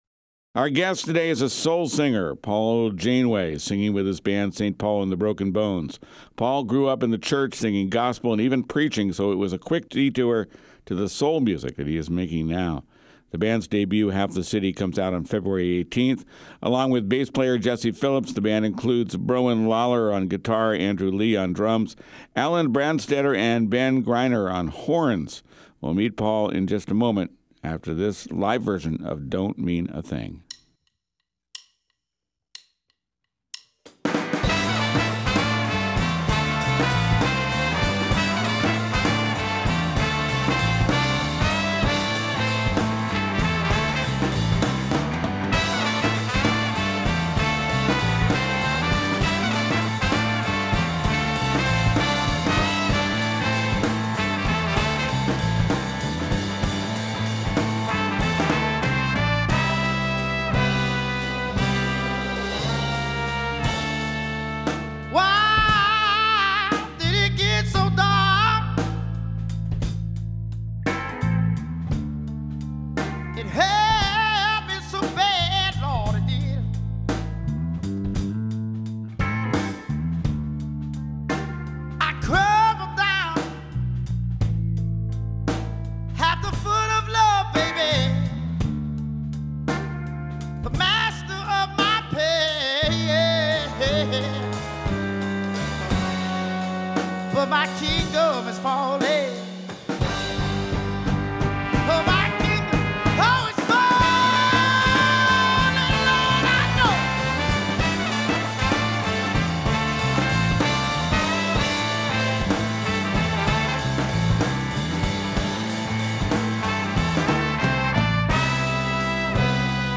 soul band